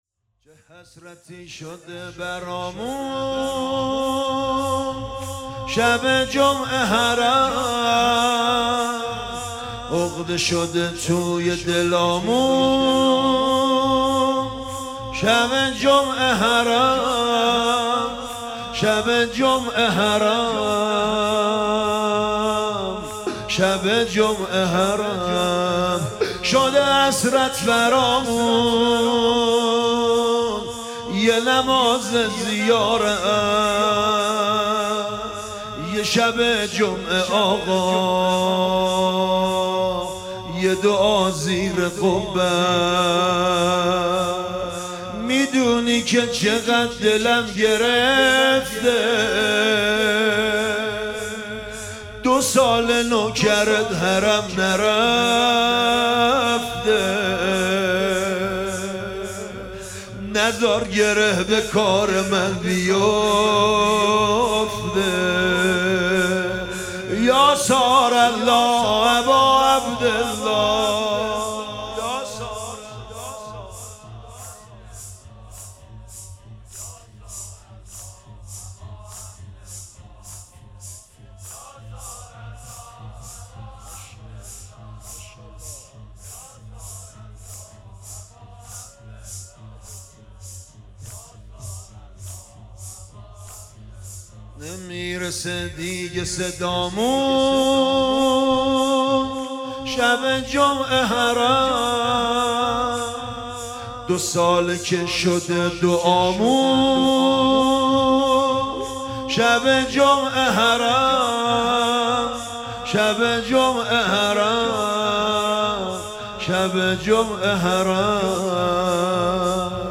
شب چهارم محرم الحرام 1443